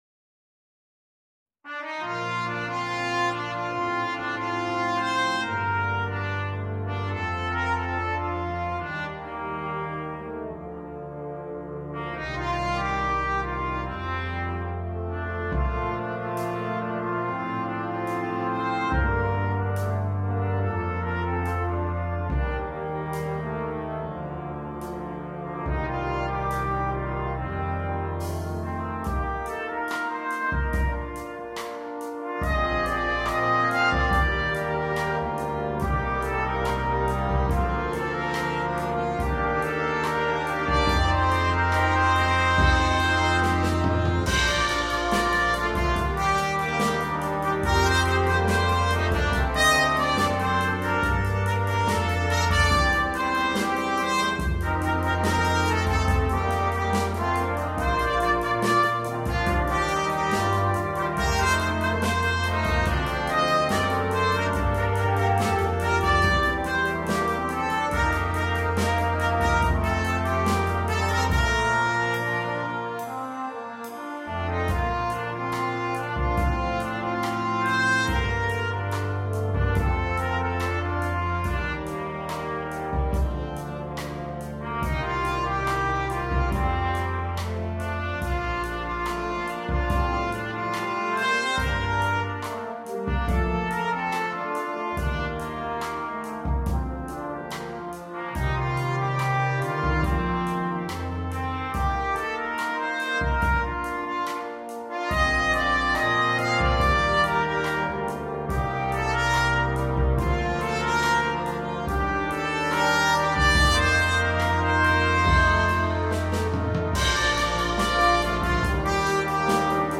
для брасс-бэнда.